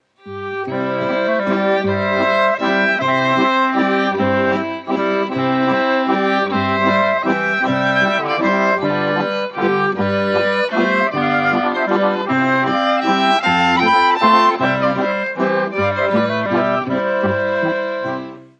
Musik aus dem Mostviertel